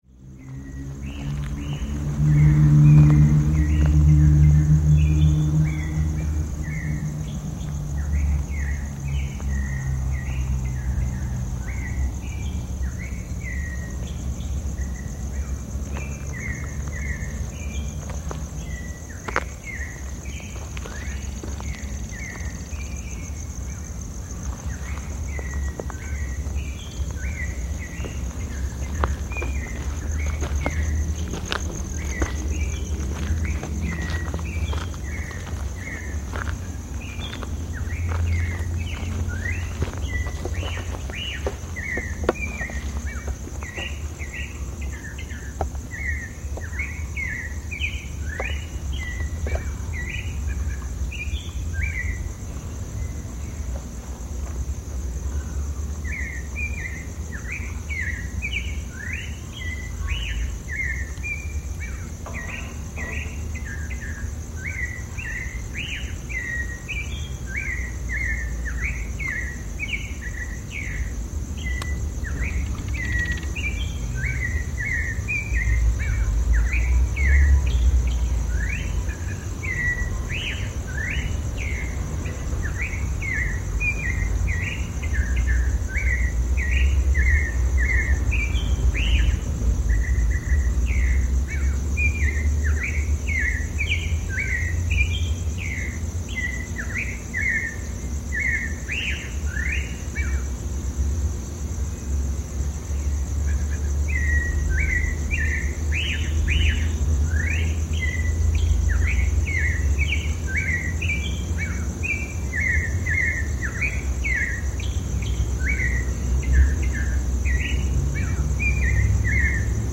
Zenzontle (toma A)
Lugar: Tuxtla Gutierrez, Chiapas; Mexico Fecha: Junio 07 de 2008. Hora: 06:10 hrs. Equipo: Minidisc NetMD MD-N707, micrófono de construcción casera ( más info ) Fecha: 2008-06-11 10:19:00 Regresar al índice principal | Acerca de Archivosonoro